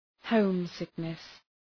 Προφορά
{‘həʋm,sıknıs}